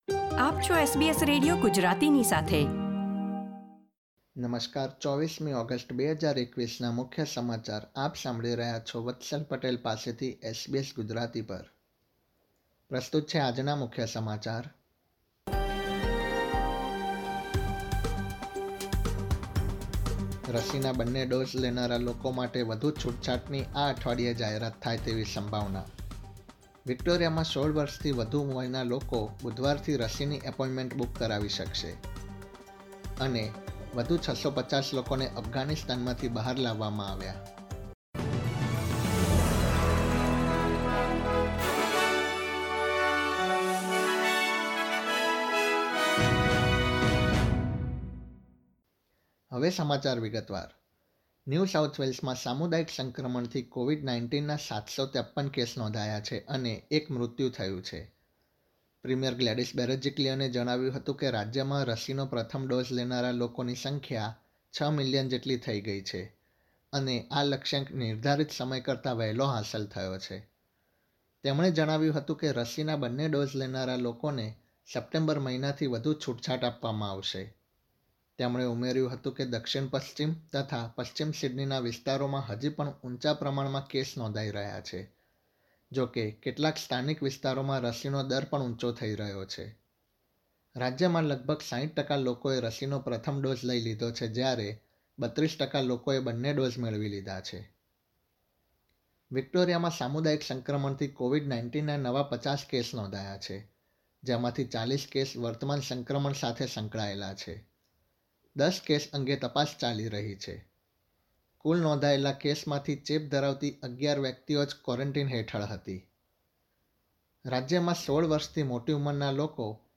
SBS Gujarati News Bulletin 24 August 2021
gujarati_2408_newsbulletin_0.mp3